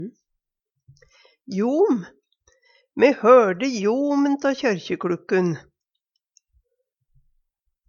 jom - Numedalsmål (en-US)